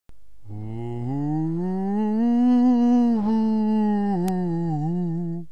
spook.wma